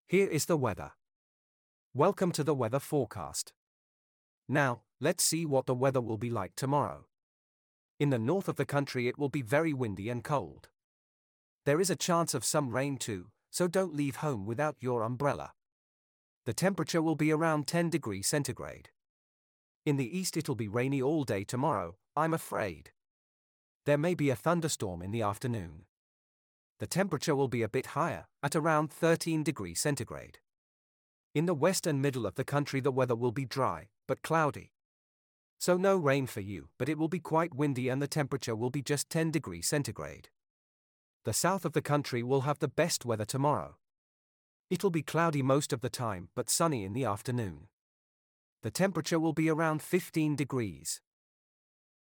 The_weather_forecast.mp3